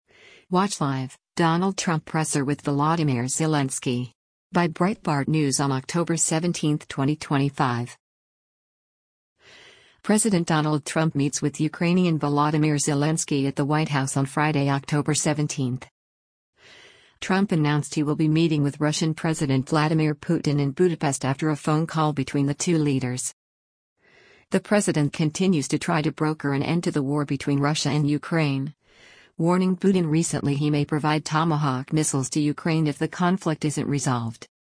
Watch Live: Donald Trump Presser with Volodymyr Zelensky
President Donald Trump meets with Ukrainian Volodymyr Zelensky at the White House on Friday, October 17.